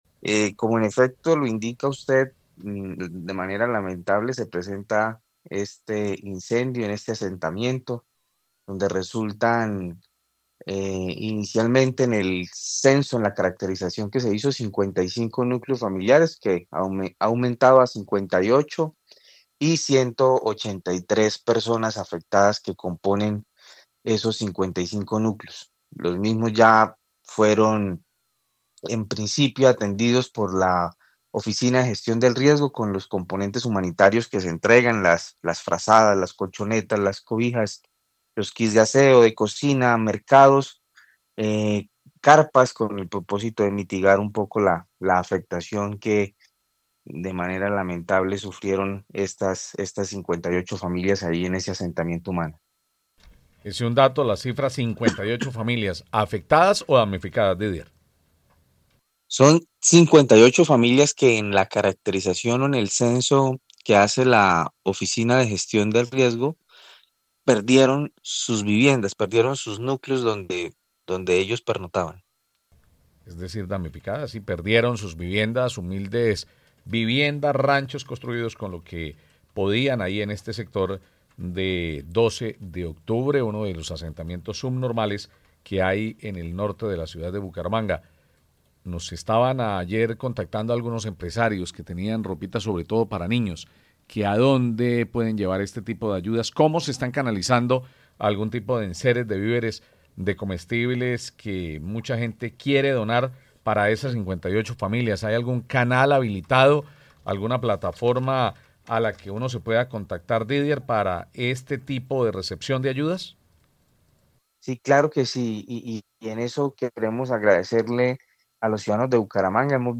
Didier Rodríguez, director Gestión del Riesgo y Desastres de Bucaramanga